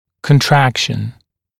[kən’trækʃn][кэн’трэкшн]сужение, уменьшение, сокращение